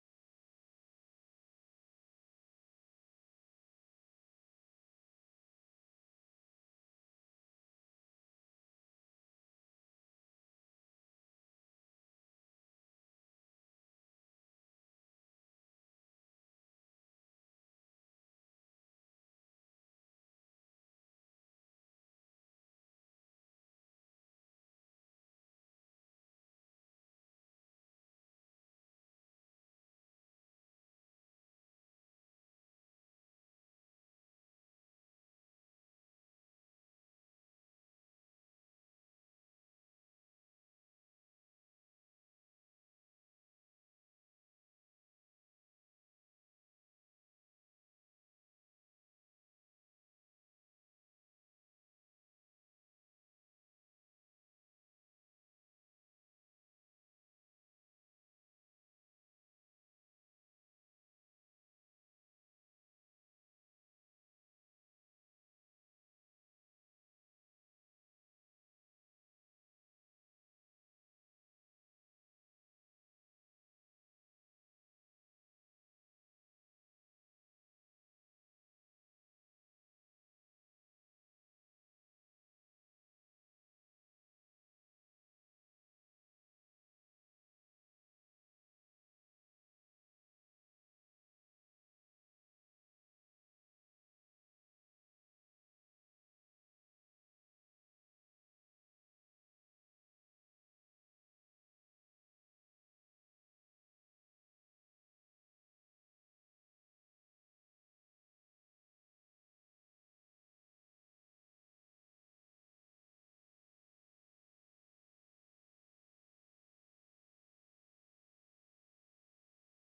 Secondary Carol Service - 2022
With performances from; Concert Band, Saxidentals, Senior Strings, Chamber Choir & Secondary Choir.